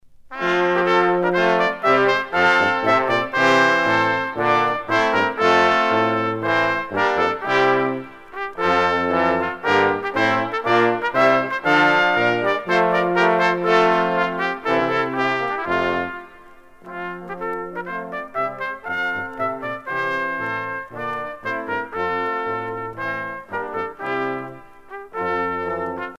Quintette de cuivres
Pièce musicale éditée